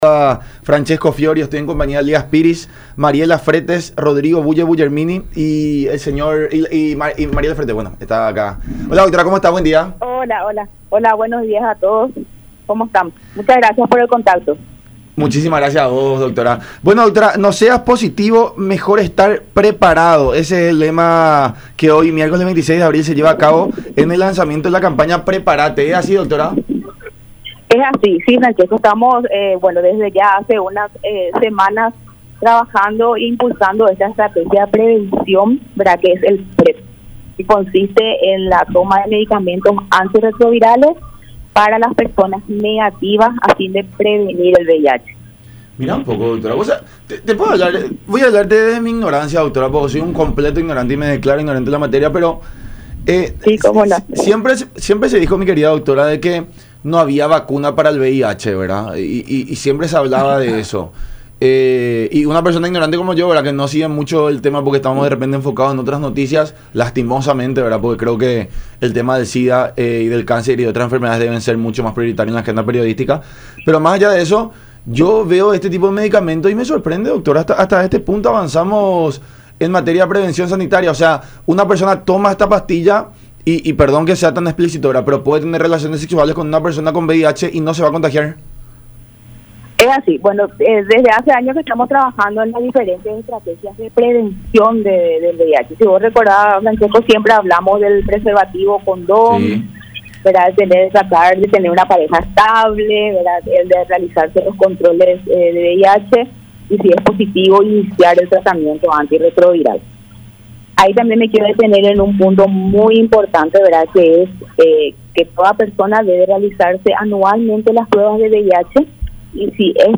“Requiere una serie de seguimientos al paciente porque la persona tiene que acceder al servicio, tiene que hacerse la prueba rápida para VIH y, si da negativo, entonces es candidata a acceder a la medicación antirretroviral, que es la combinación de dos medicamentos”, dijo Samudio en diálogo con La Unión Hace La Fuerza por Unión TV y radio La Unión, indicando que el comprimido está siendo distribuido en ciertos servicios de salud del país “porque de un seguimiento de la persona”.